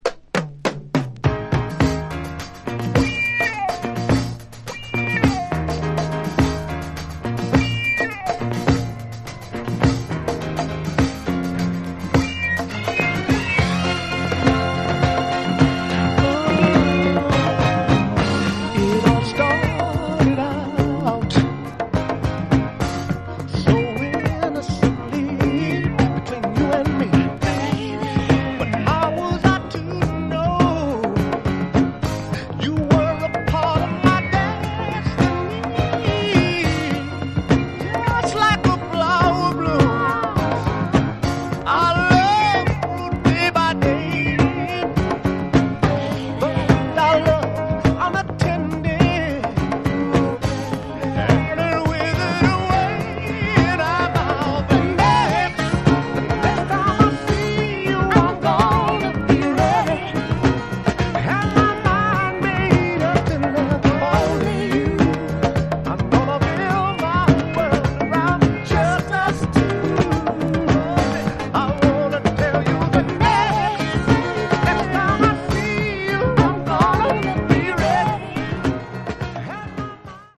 Essential LP for the soul boys and girls.